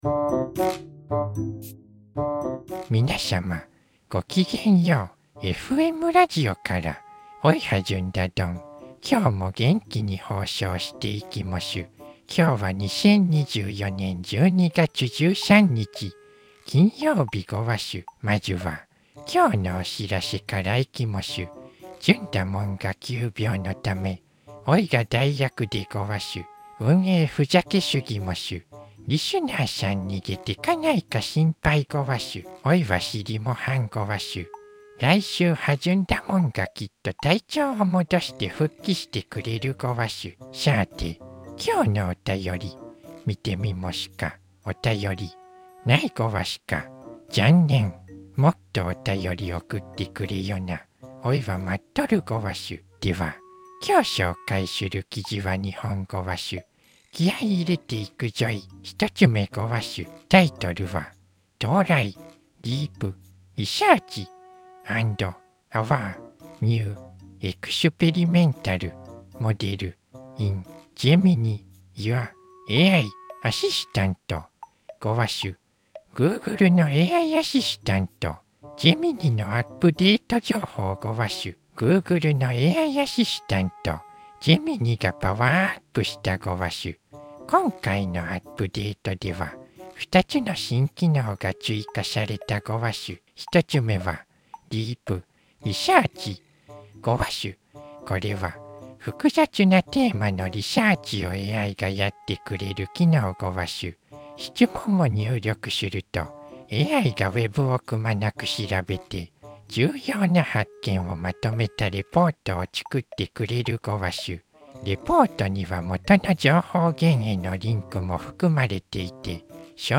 ずんだもんが急病のため引き続き急遽代役で、おいはずんだどんごわす。